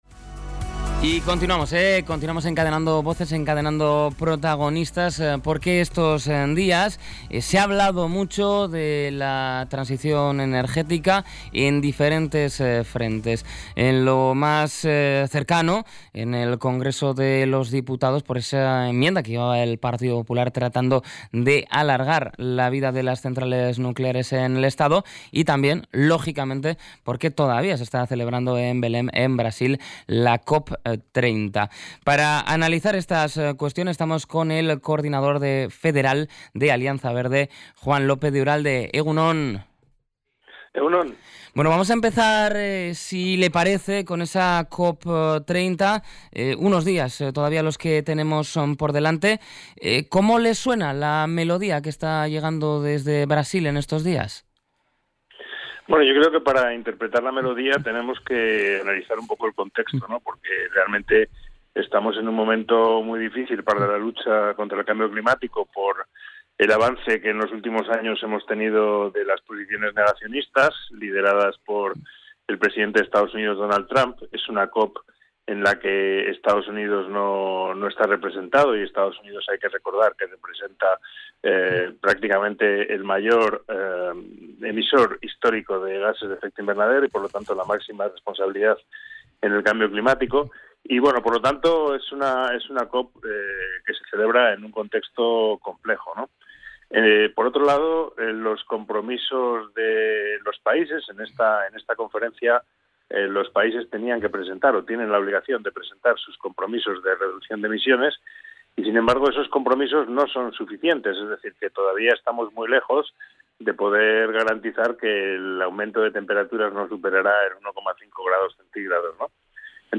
ENTREV.-JUAN-LOPEZ-URALDE.mp3